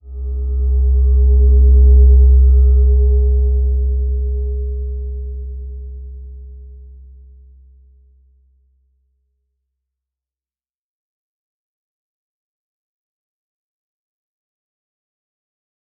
Slow-Distant-Chime-C2-f.wav